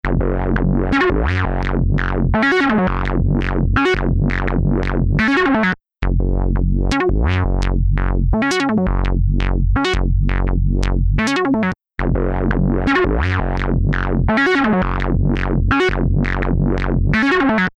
パワフルなディストーション＆サチュレーション・エンジン
CrushStation | Bass Synth | Preset: Punk Rock Lobster
CrushStation-Eventide-Bass-Synth-Punkrock-Lobster.mp3